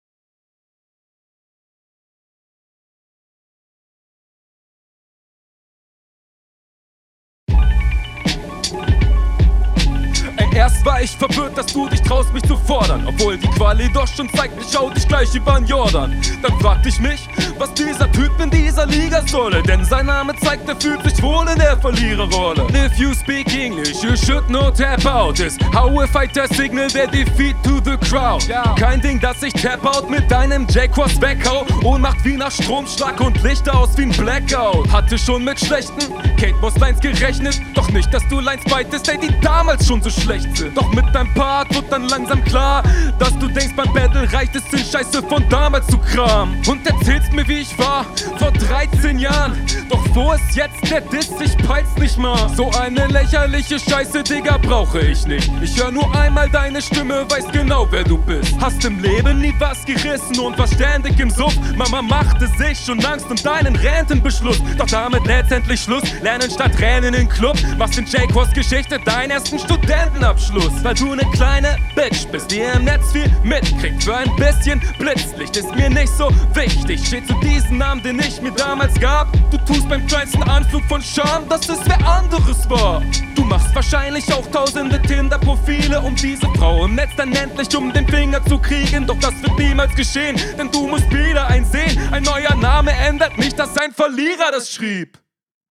Audiotechnisch seh ich dich hier vorne, das Rauschen liegt wohl am Beat.
Wie auch bei deinem Gegner ist die Soundqualität okay, aber auch ausbaufähig.
Abmische ist bei dir deutlich besser. Flow Text und Stimme auch überlegen.